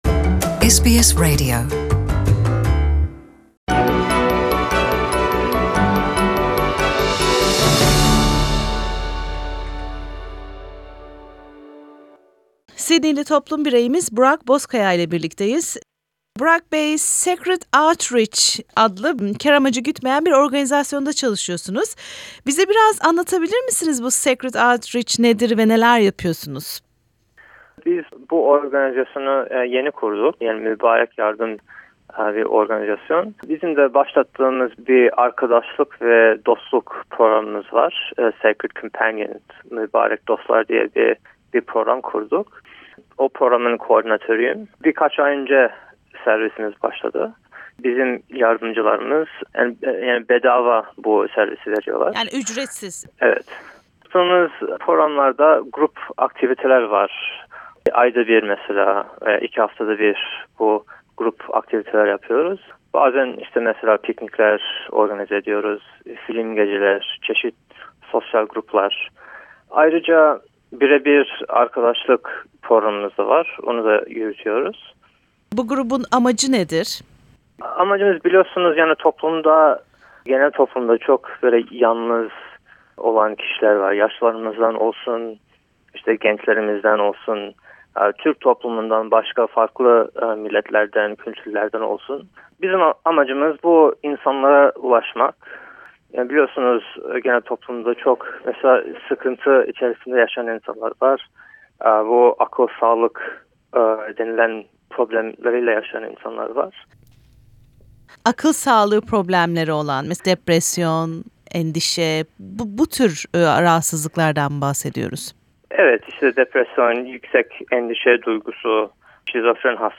Sacred Companions is a volunteer friendship program providing one to one and group support to individuals of various age groups who are experiencing mental health issues, isolation and loneliness. Interview